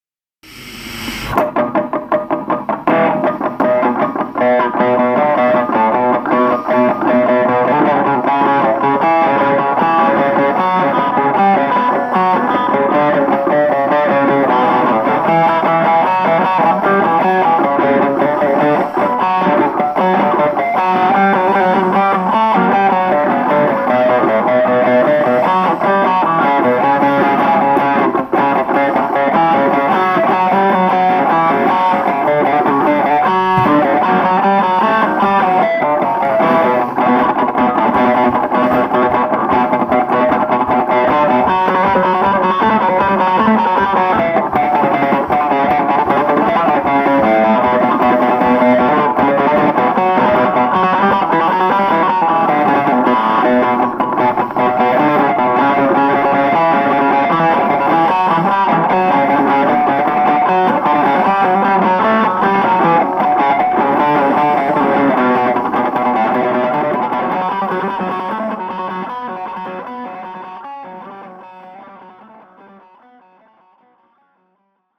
Имхо, самый классный перегруз, это  в педаль перегруза с али.